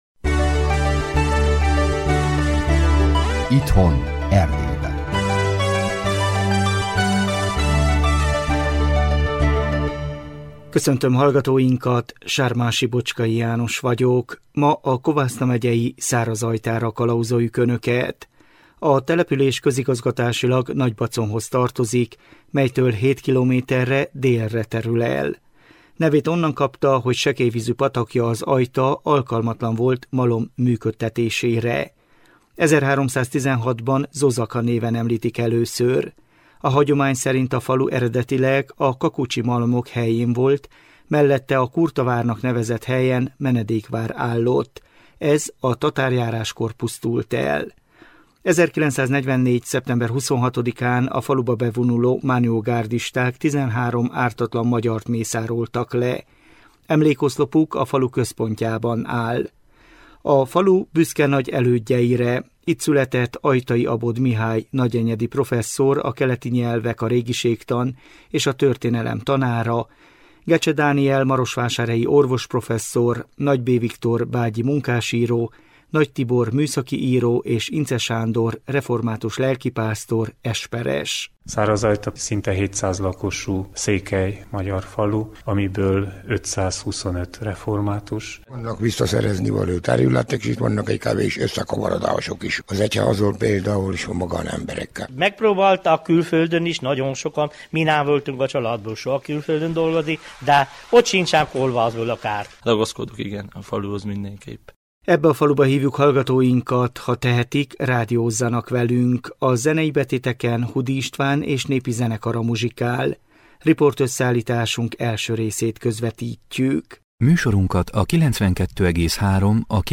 Műsorunk a 2024 október 27-i műsor ismétlése!